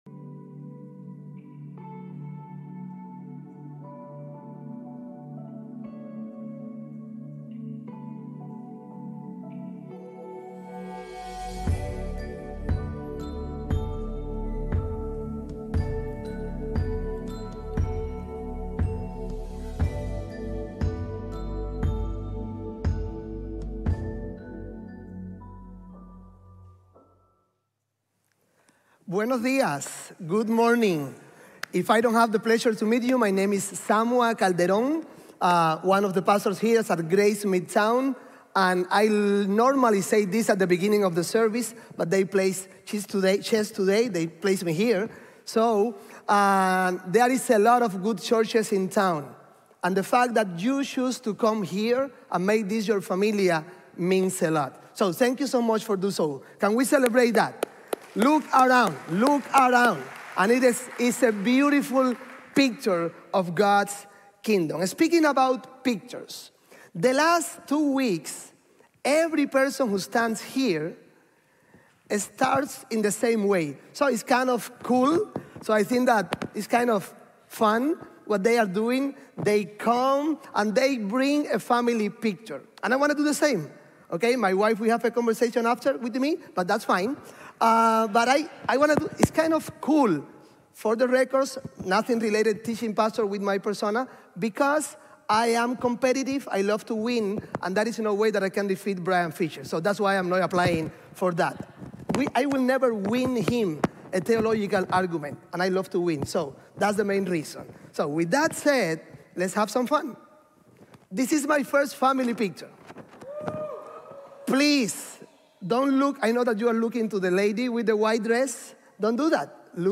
We…matter for Jesus | Sermon | Grace Bible Church